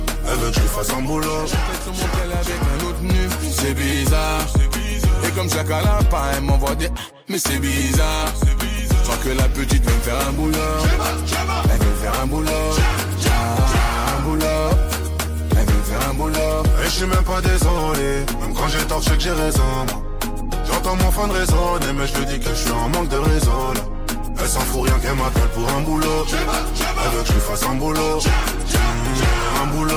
Жанр: Африканская музыка
# Afrobeats